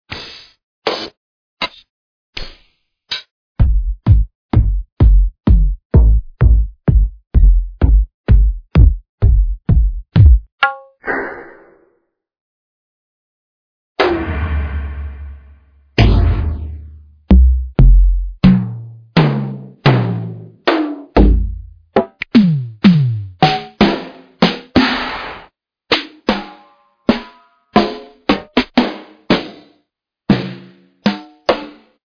Including Kicks, Claps, Hihats and Percussion samples. Packed with 50 designed and layered drum shots.
Fits perfect for Mainroom House/Deep House/Tech-House/Minimal/Techno/Dubstep and more.
(the prelisten files are in a lower quality than the actual packs)
Such a kick drum will usually sound much more fat than just a raw sound.
flph_layereddrums3_showcase.mp3